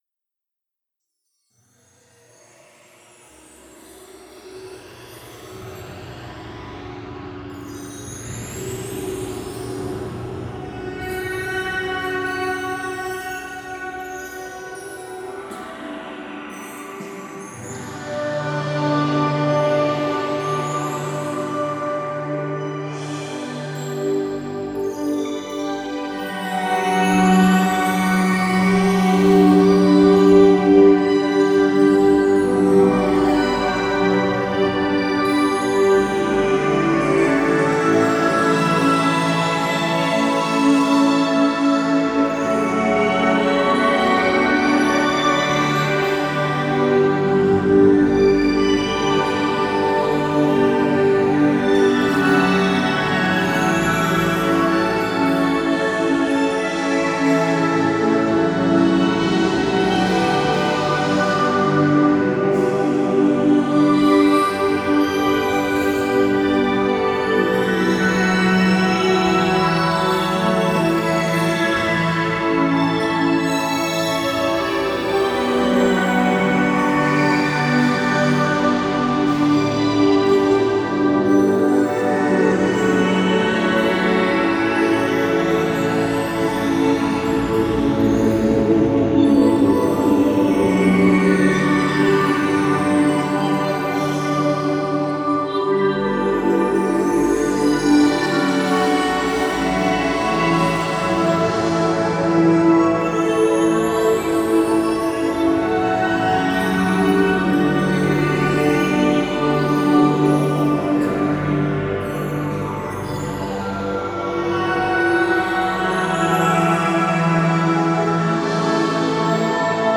the 9 tracks here twist and melt from evocative synthscapes
Guitar, Synths, Vocals, Programming & Production
Keyboards, Programming